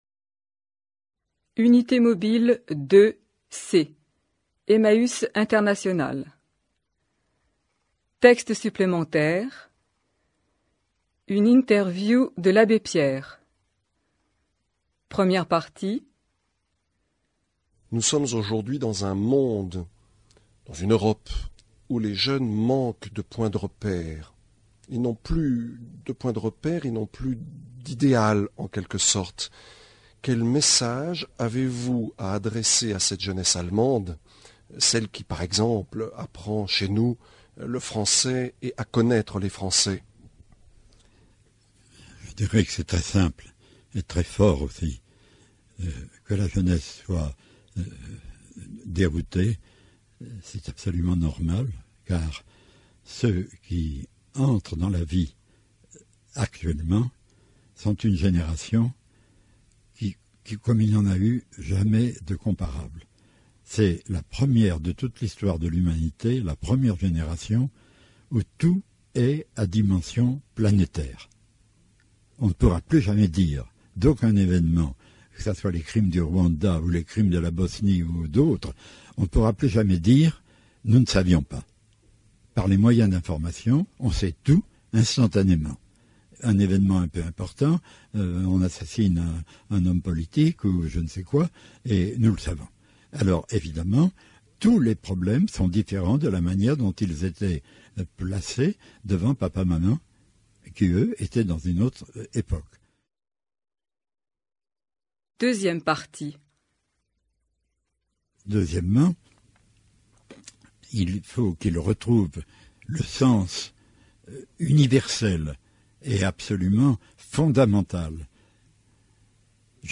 > Interview mit Abbé Pierre, 1997 *.mp3